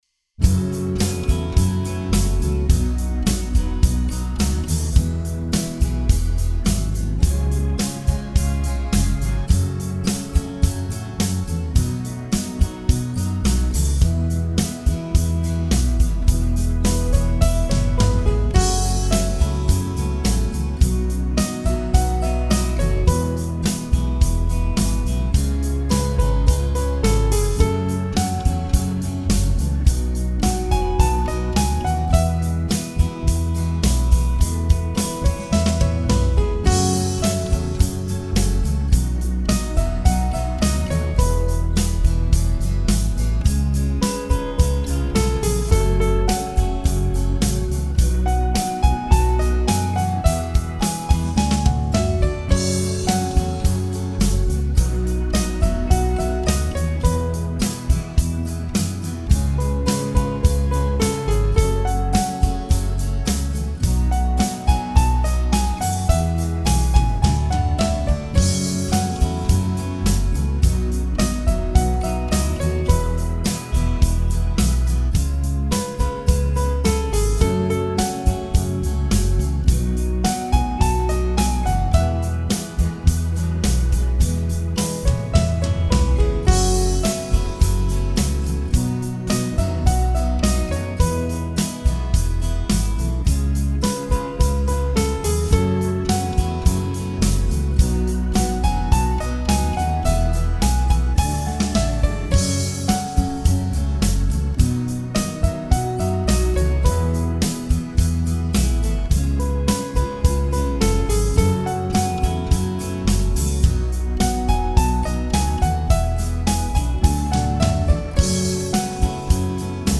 Der spilles som originalen
Der spilles lidt forspil –
Start med at synge efter 16 sekunder